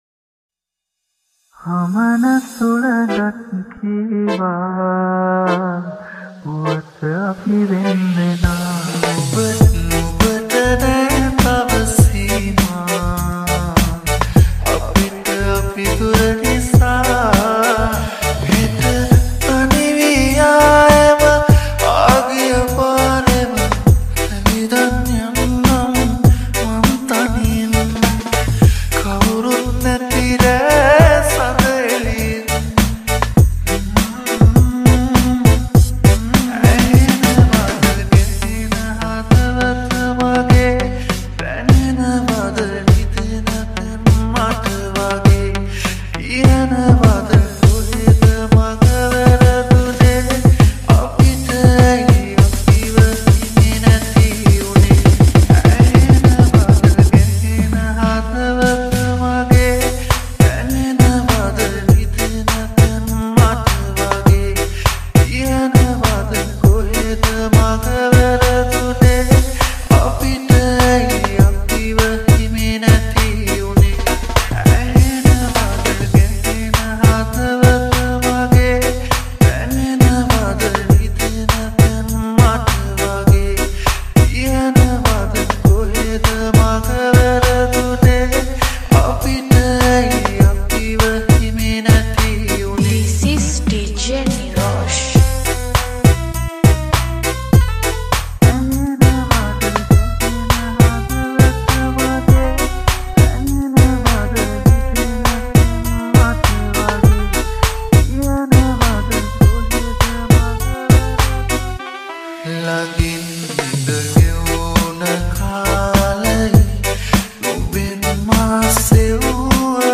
Raggae Type Beat Remix